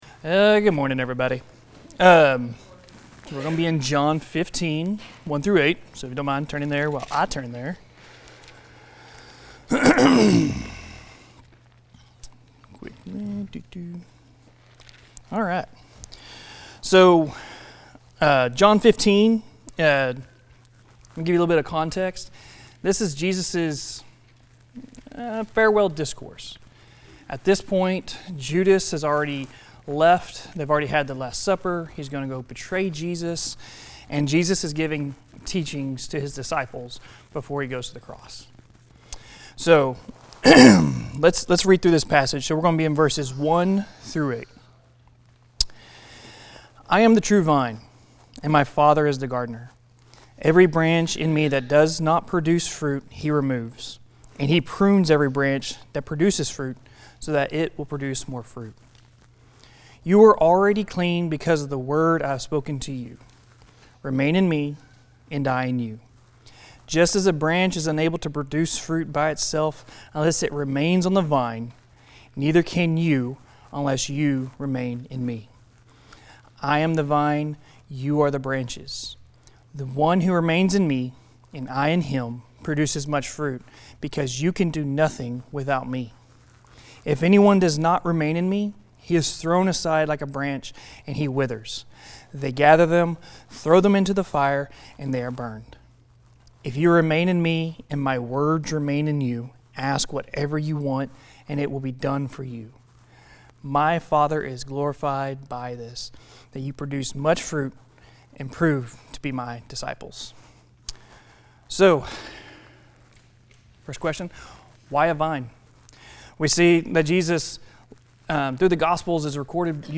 Guest teacher